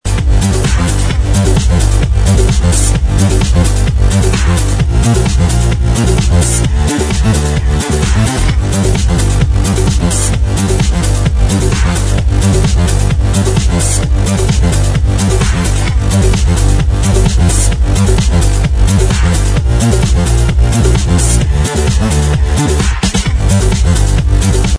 House/Electro May 2006 ~ID 3~